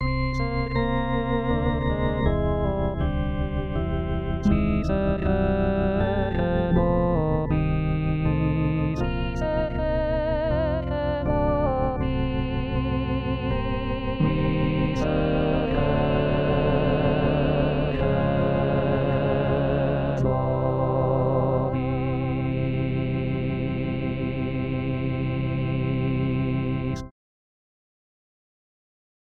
Chanté: